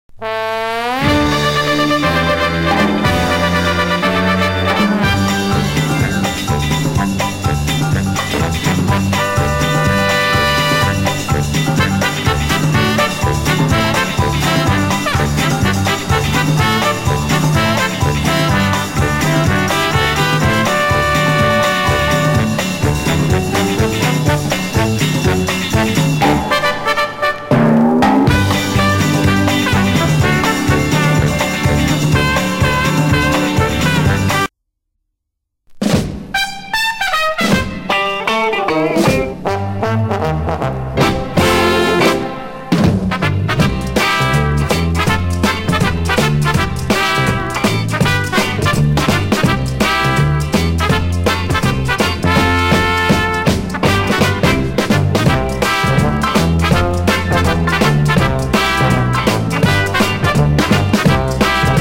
妙に歯切れの良い感じは英国的だね。
(税込￥1980)   TIJUANA STYLE INST